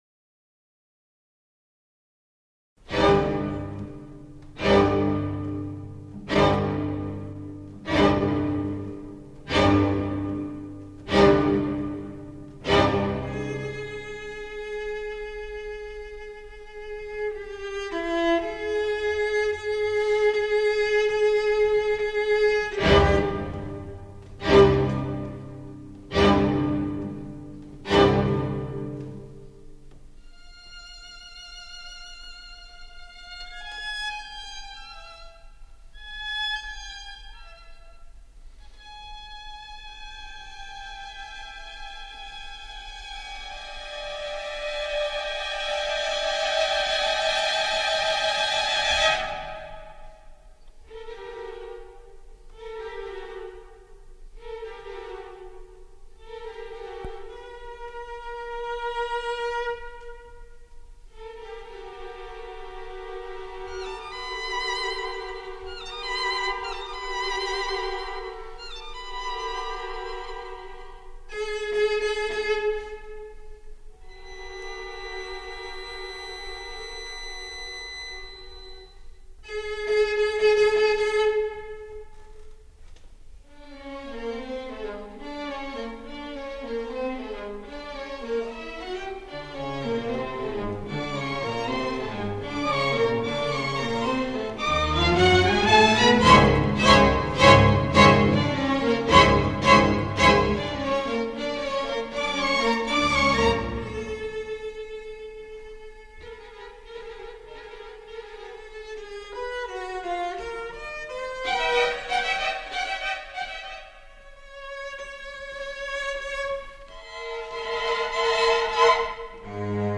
Musik für Streichquartett [ 1981 ]